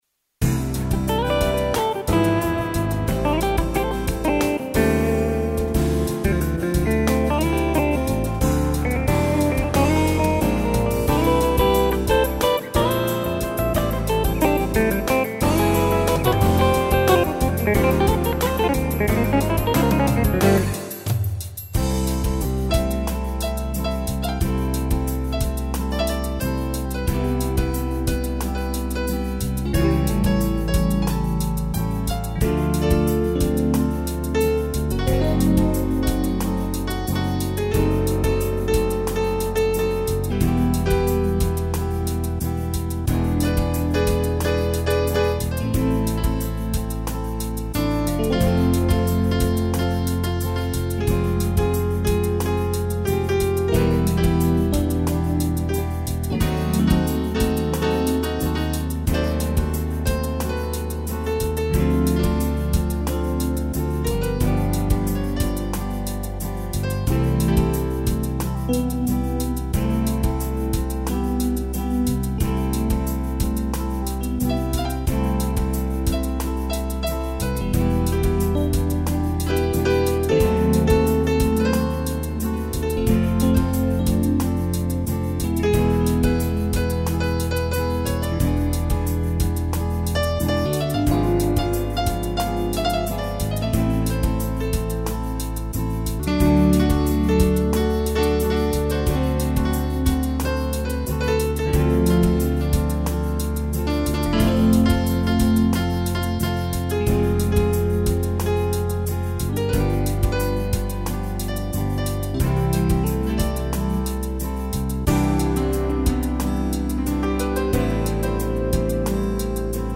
arranjo e interpretação teclado